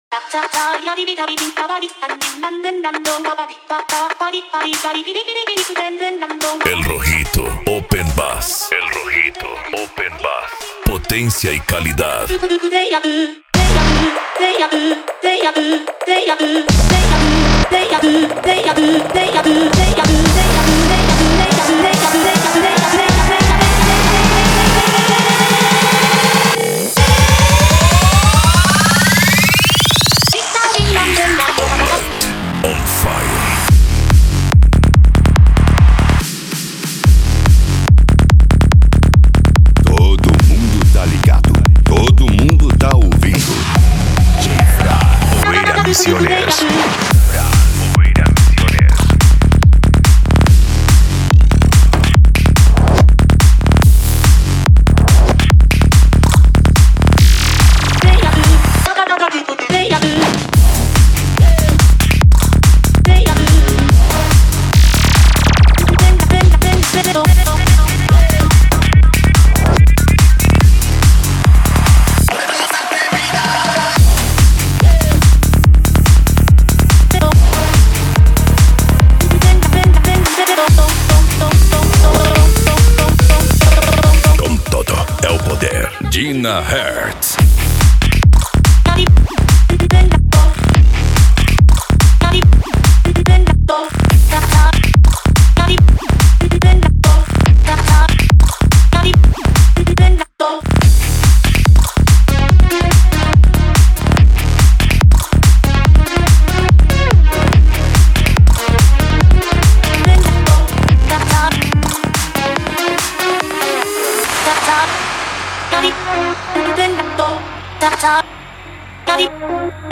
Electro House
Psy Trance
Remix